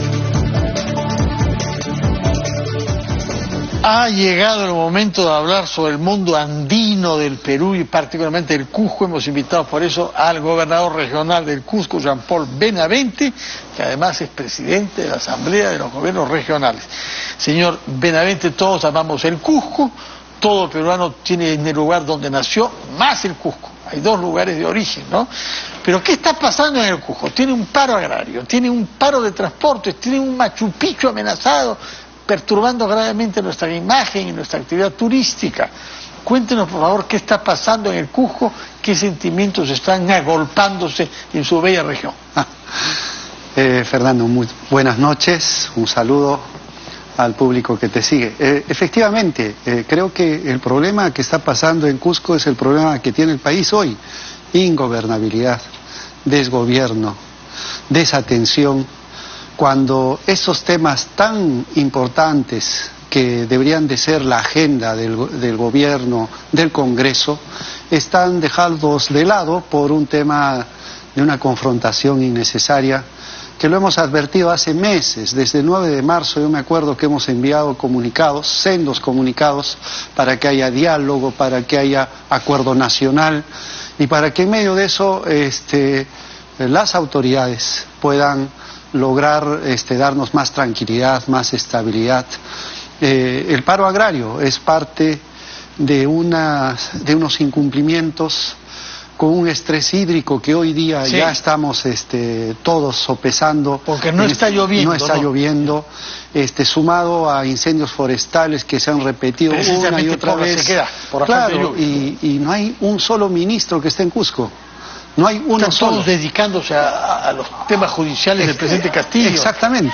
Entrevista a Jean Paul Benavente, gobernador regional de Cusco